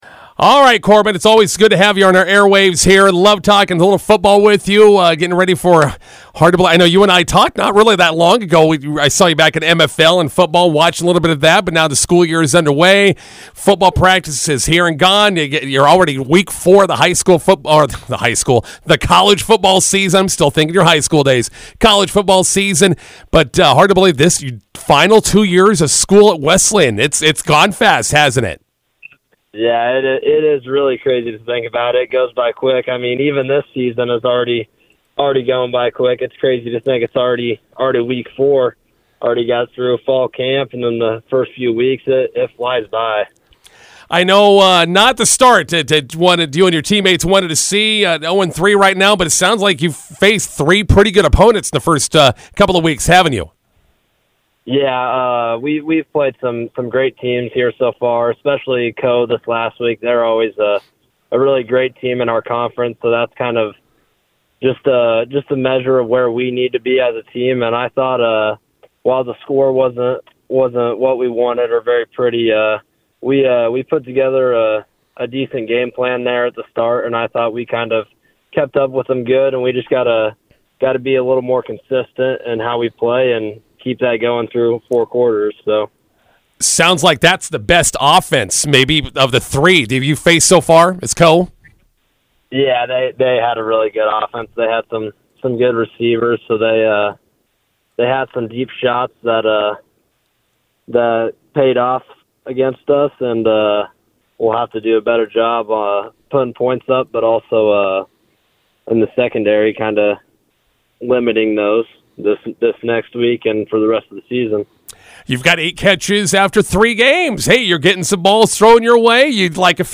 INTERVIEW: Nebraska Wesleyan football prepares for American Rivers Conference foe Luther College this Saturday.